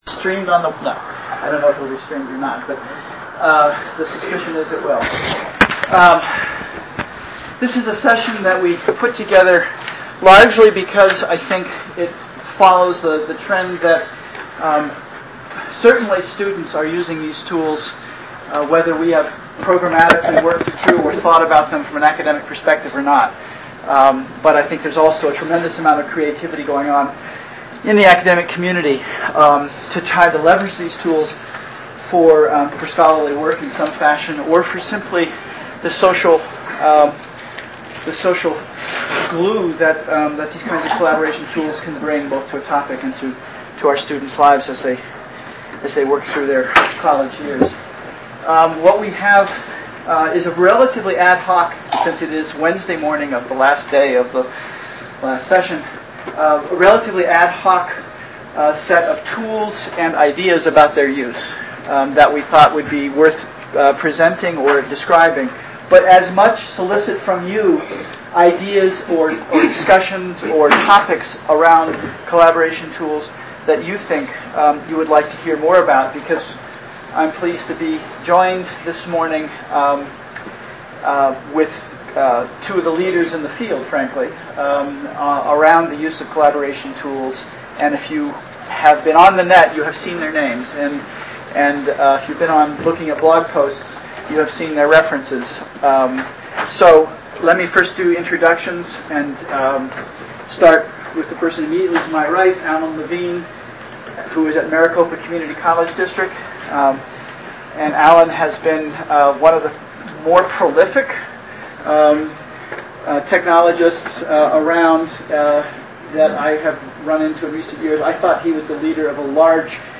Seminars on Academic Computing, Snowmass, Colorado, Panel, Aug 09, 2005.